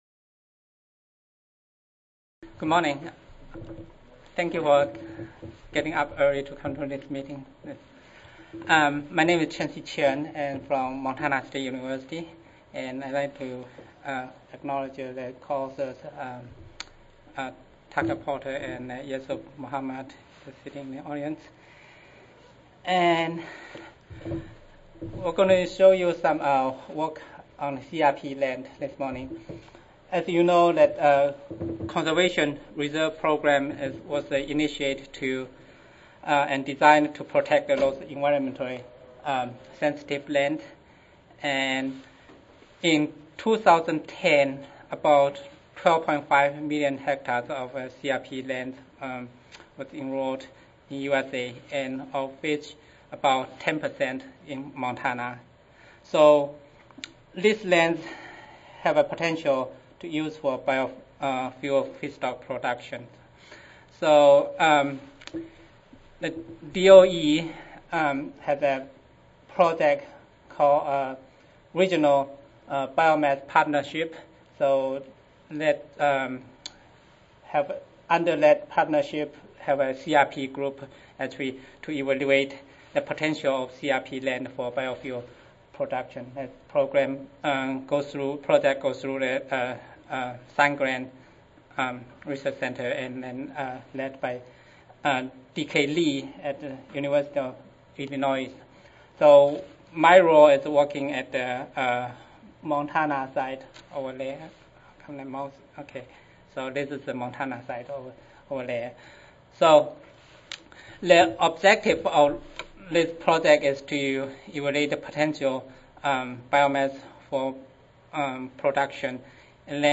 Montana State University Recorded Presentation Audio File